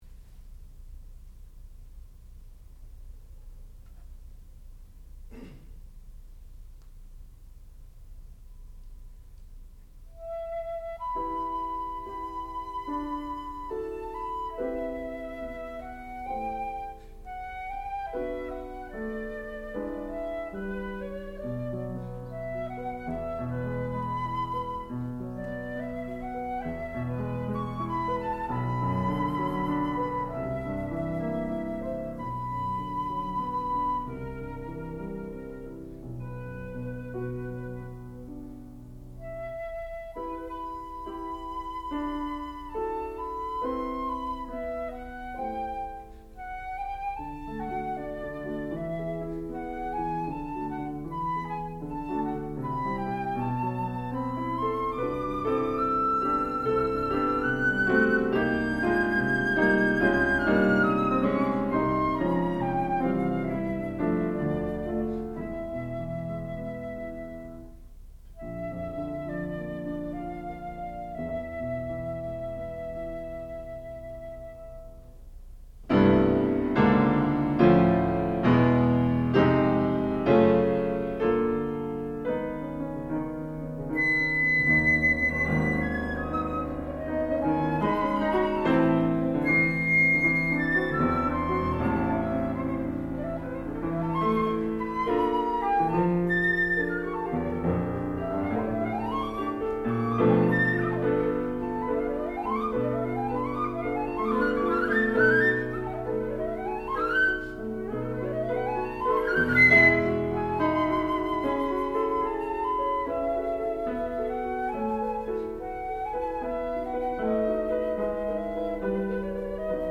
sound recording-musical
classical music
flute
piano
Master's Recital